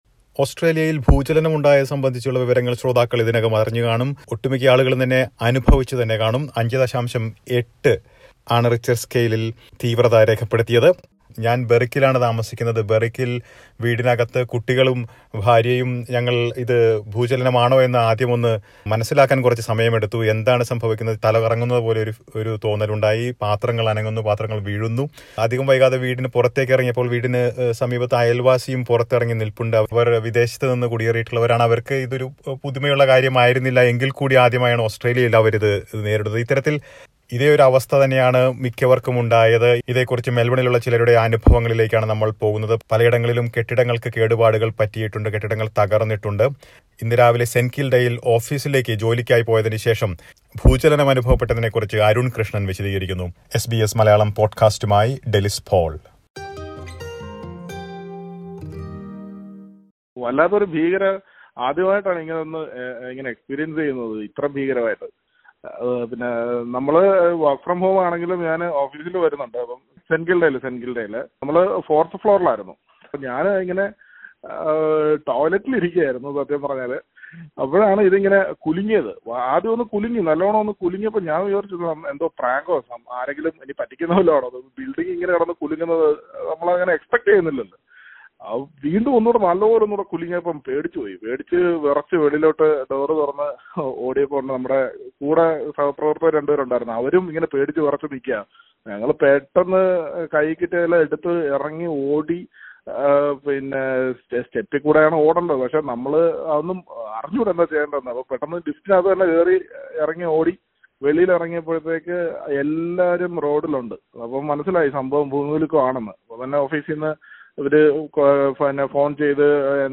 ഭൂചലനവുമായി ബന്ധപ്പെട്ടുണ്ടായ ആശങ്കകളും അനുഭവങ്ങളും മെല്‍ബണിലുള്ള മലയാളികള്‍ എസ് ബി എസ് മലയാളത്തോട് പങ്കു വെക്കുന്നത് കേള്‍ക്കാം, മുകളിലെ പ്ലയറിൽ നിന്ന്.